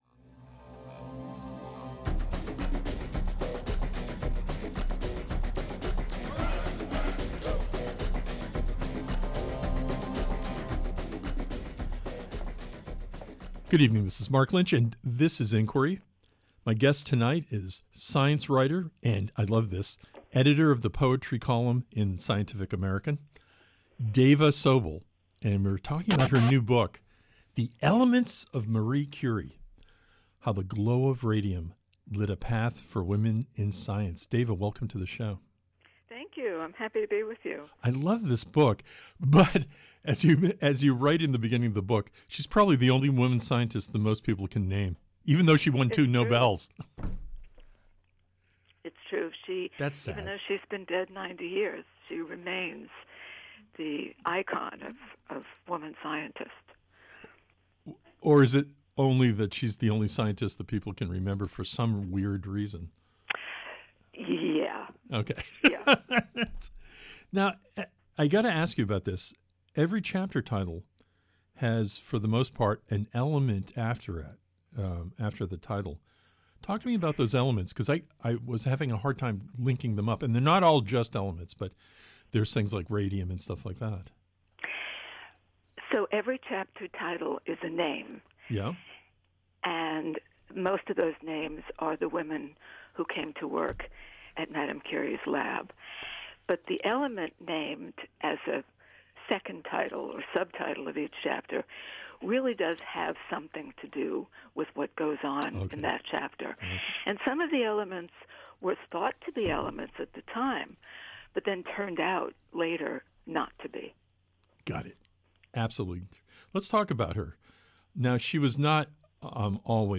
On this episode of Inquiry, we talk with science writer and editor of the poetry column in Scientific American DAVA SOBEL about her new biography: THE ELEMENTS OF MARIE CURIE: HOW THE GLOW OF RADIUM LIT A PATH FOR WOMEN IN SCIENCE.